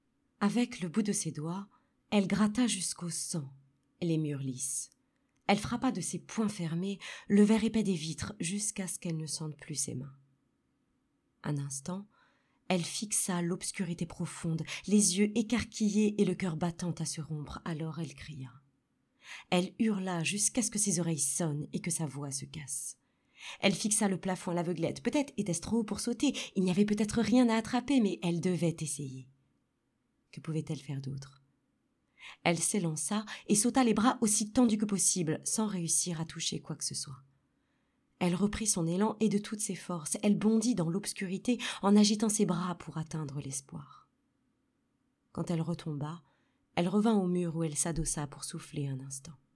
Lecture livre
17 - 50 ans - Soprano